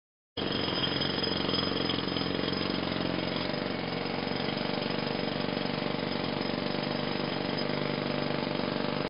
جلوه های صوتی
دانلود صدای هیلتی یا چکش مکانیکی 2 از ساعد نیوز با لینک مستقیم و کیفیت بالا